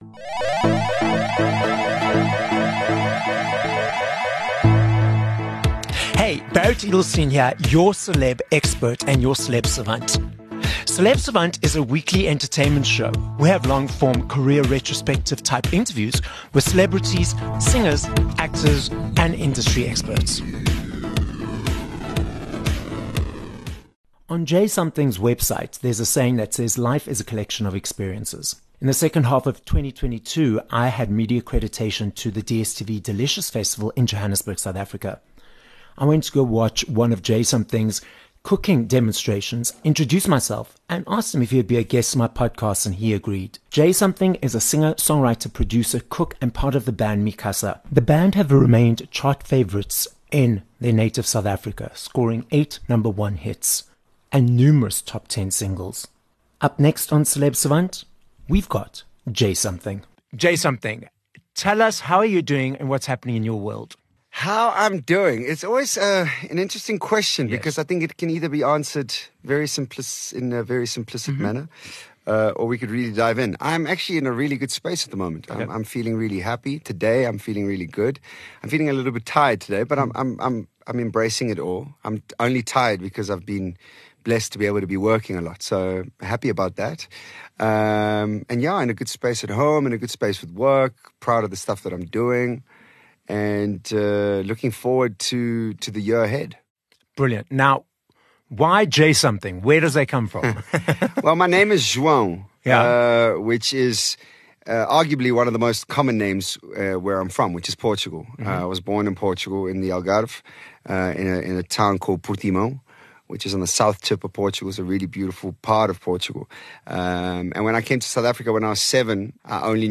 6 Feb Interview with J'Something